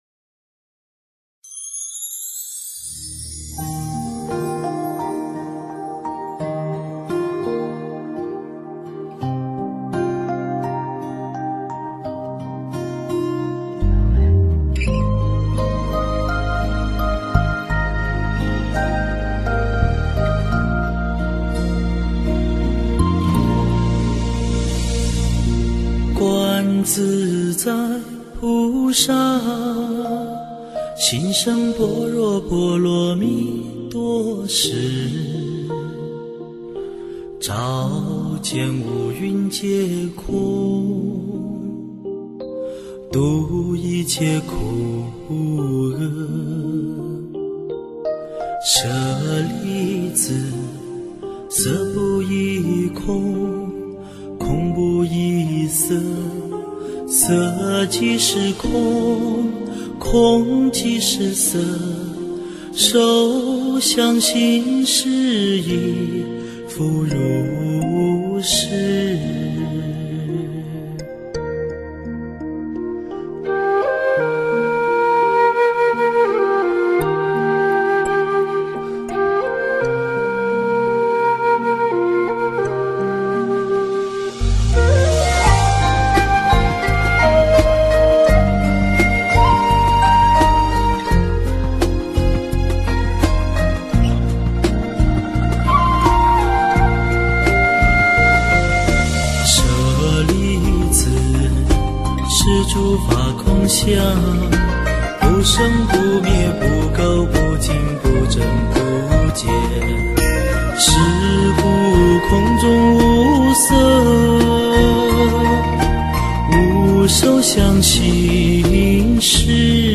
诵经
标签: 佛音诵经佛教音乐